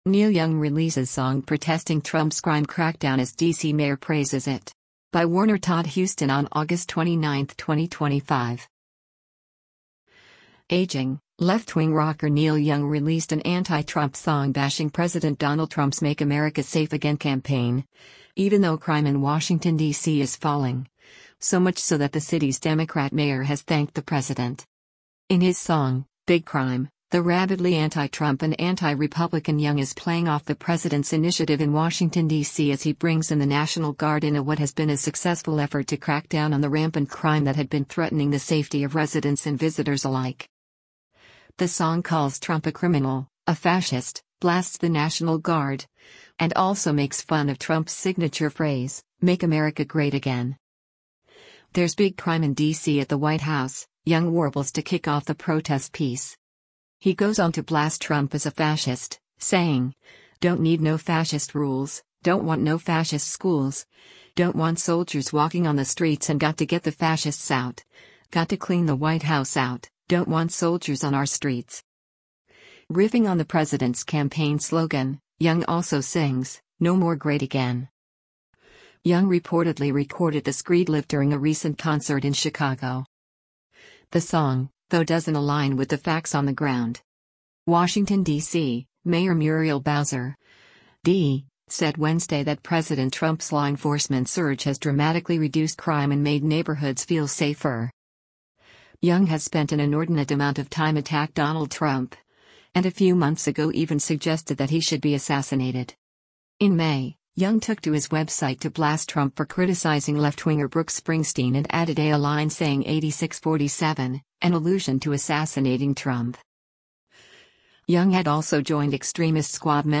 left-wing rocker